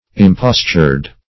Impostured \Im*pos"tured\, a.